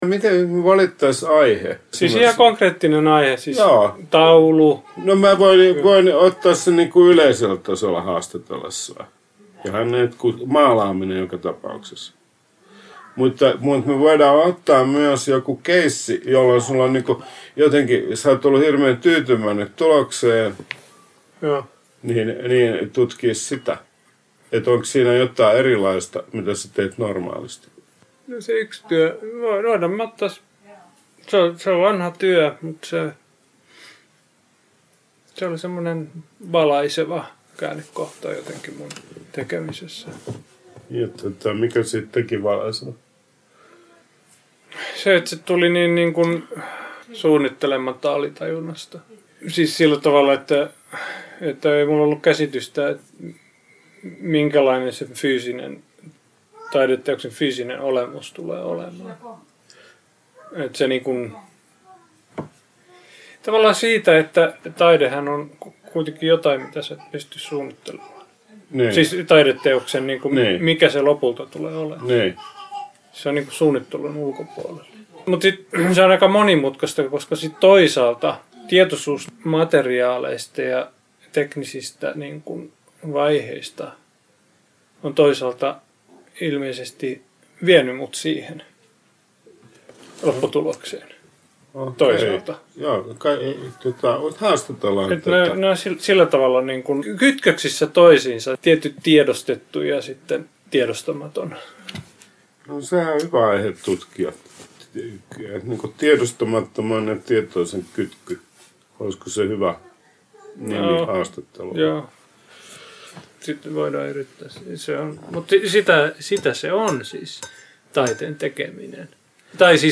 Villa Kiven tietokirjailijaillassa perehdyttiin Tove Janssonin elämään ja taiteeseen